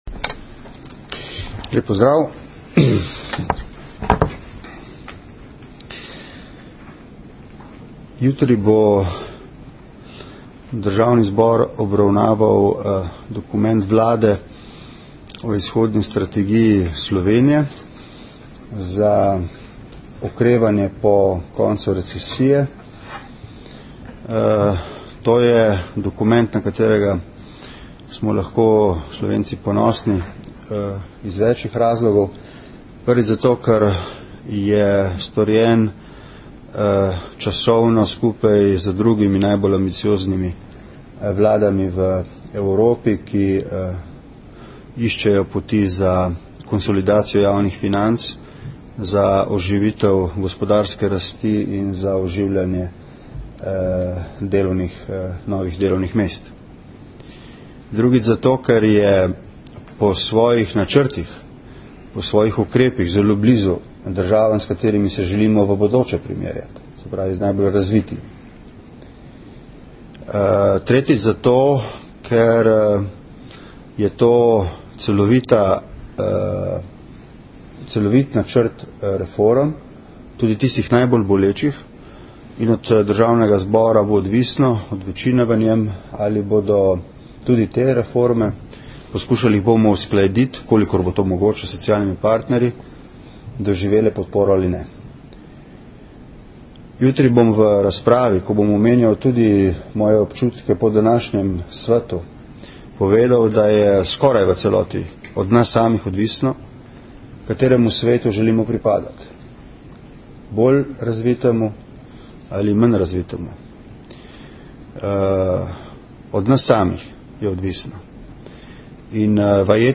Zvočni zapis izjave predsednika Vlade RS Nazaj na seznam